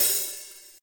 drum-hitfinish.ogg